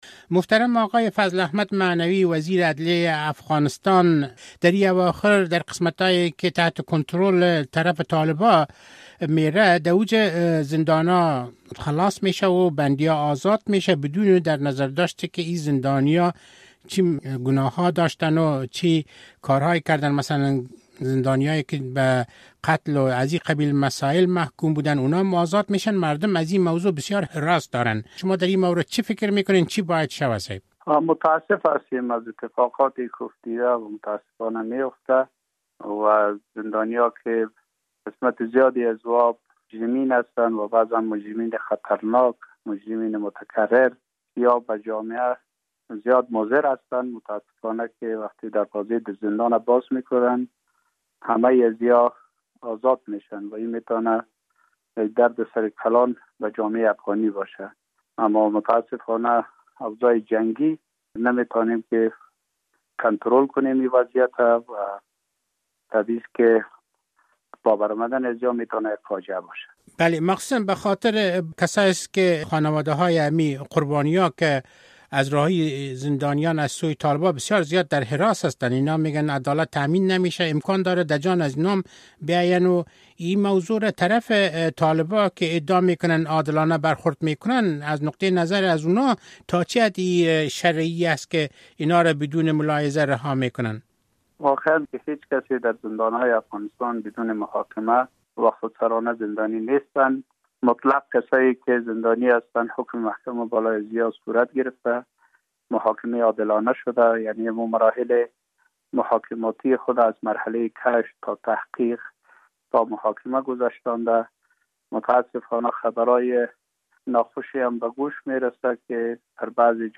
رهایی زندانیان جرمی می‌تواند یک فاجعه باشد. این مطلب را فضل احمد معنوی٬ وزیر عدیه افغانستان در گفت‌وگوی اختصاصی به رادیو آزادی گفت.